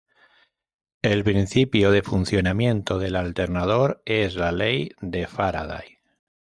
fun‧cio‧na‧mien‧to
/funθjonaˈmjento/